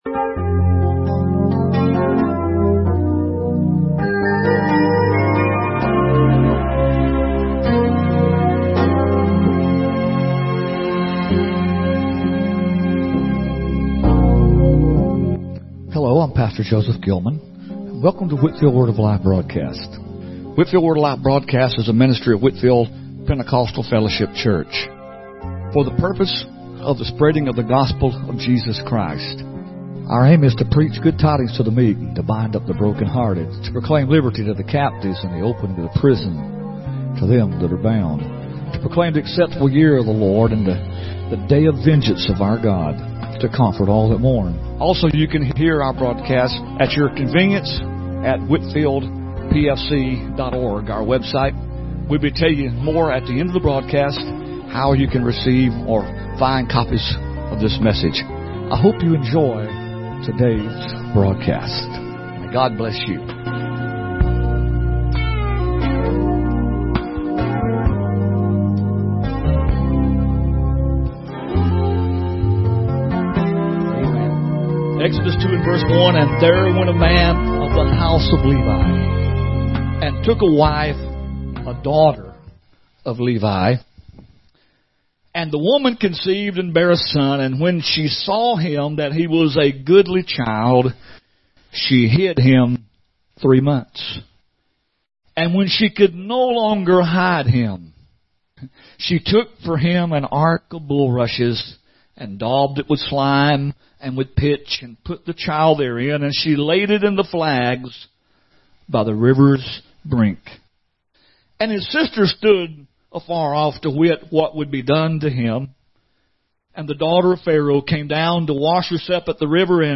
Sermon and Events Videos Services Word of Life Broadcast 05-11-25 The Characteristics Of A Godly Mother